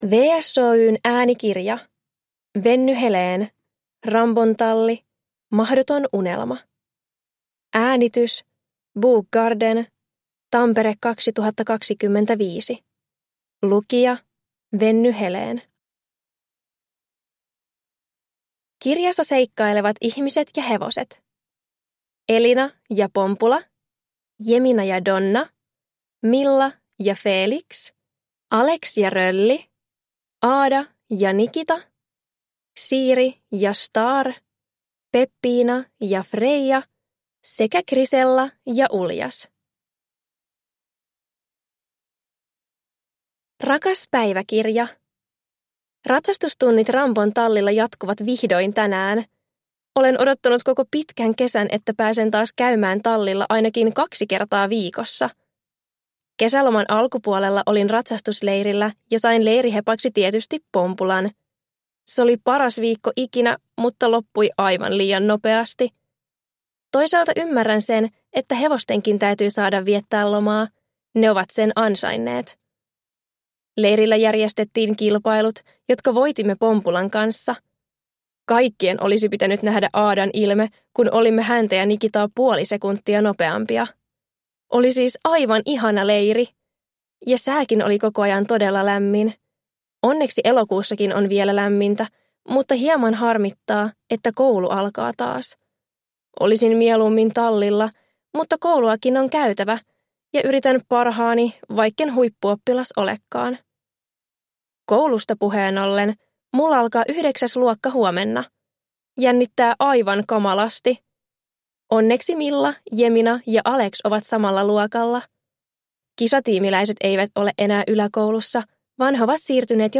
Rambon talli: Mahdoton unelma – Ljudbok